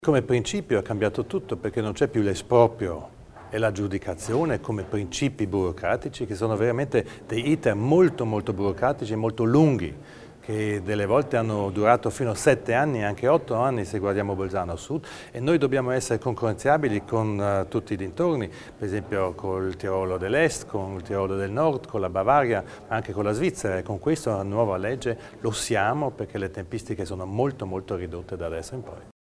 L'Assessore Widmann spiega le novità per le aree produttive